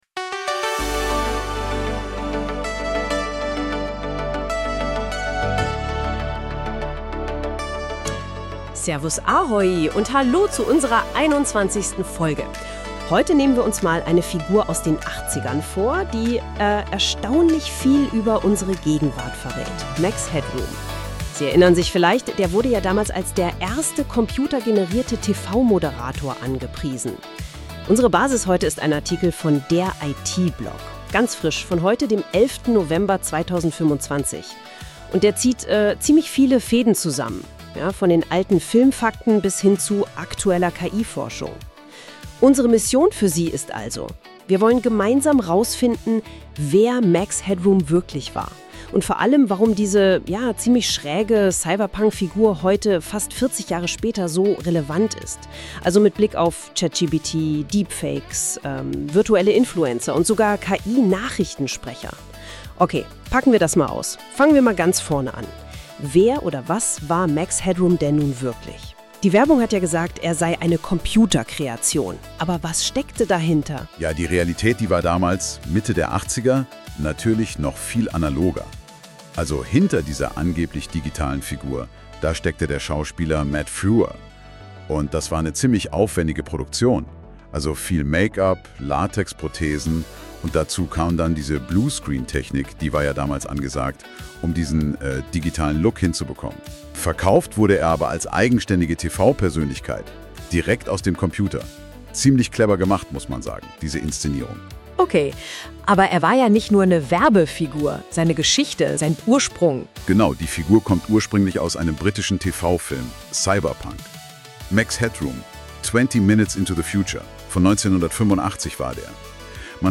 Dieser Podcast ist Erstellt mit ChartGPT und NotebookML sowie Ideogram-AI .